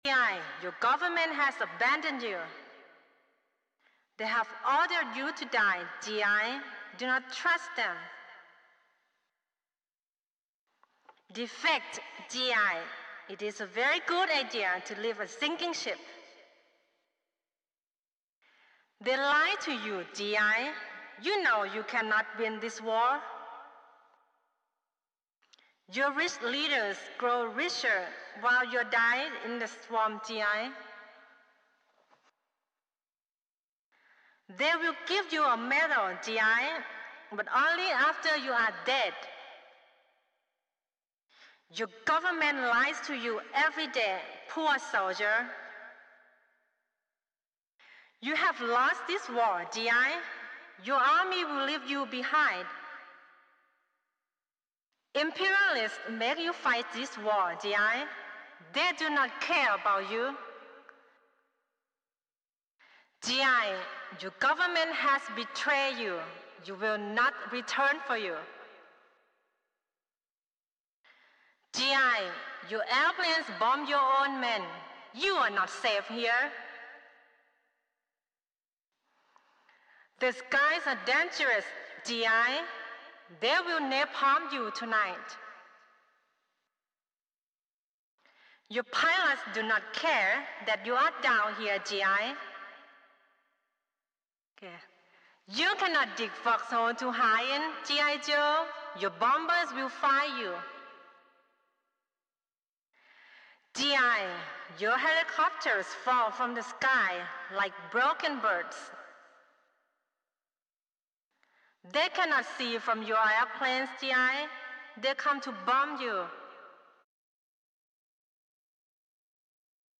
Audio of "Tokyo Rose" on Radio Tokyo - WWII Propaganda Announcer